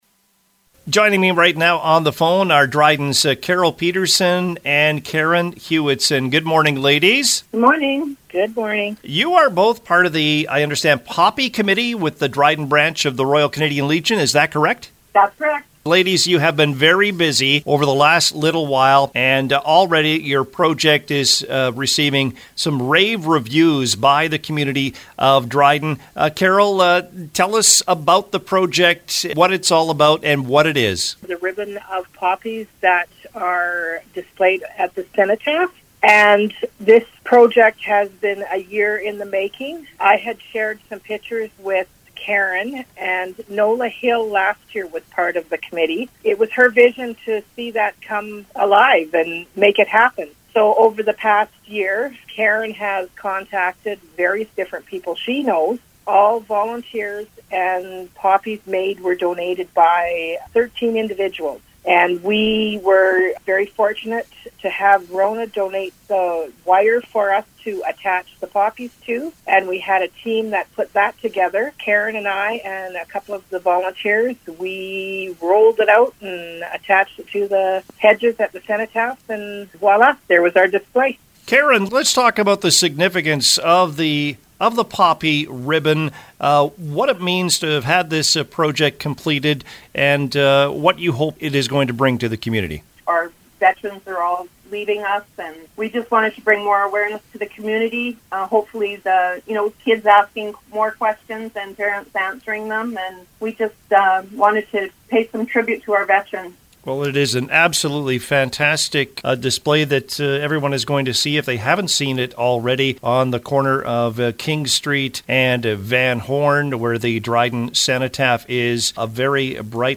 were guests on the CKDR Morning Show Tuesday to promote the campaign in Dryden.